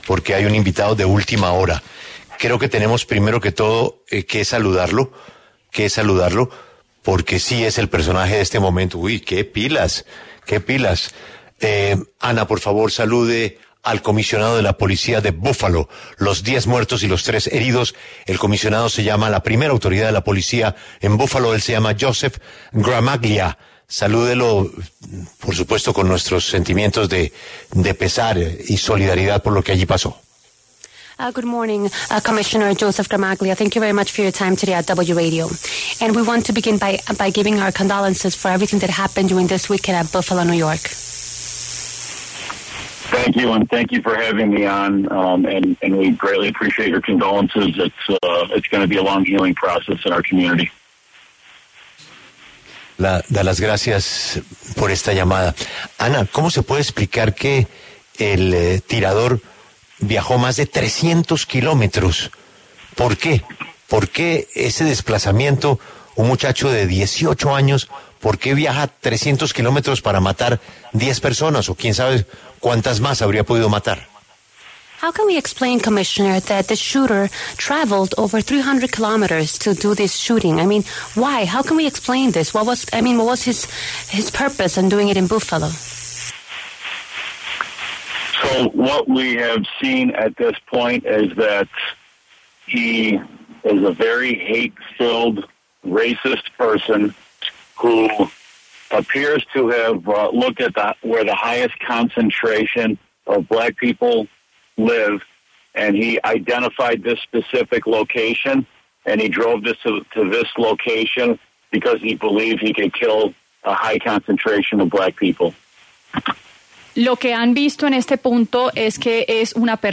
Joseph Gramaglia, comisionado de la Policía de Buffalo, habló en La W sobre el tiroteo que dejó al menos 10 personas fallecidas.
En el encabezado escuche la entrevista completa con Joseph Gramaglia, comisionado de la Policía de Buffalo, Nueva York.